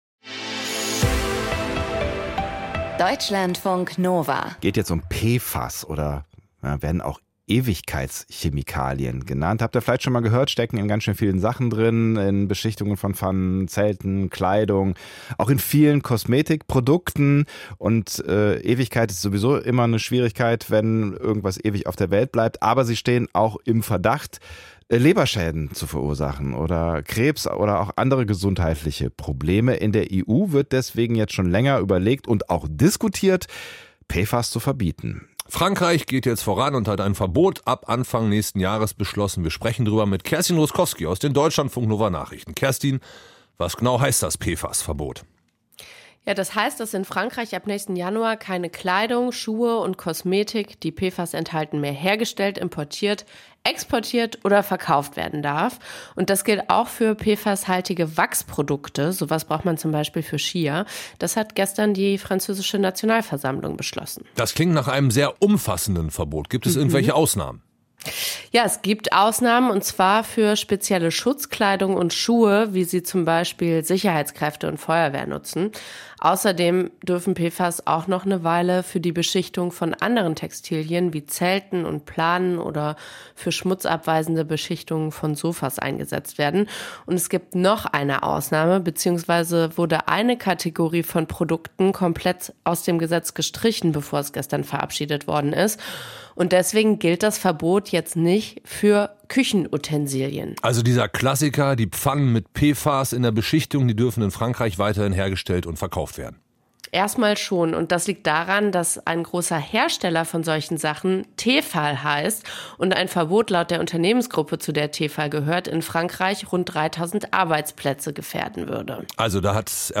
Kommentar zu PFAS - Das Asbest unserer Zeit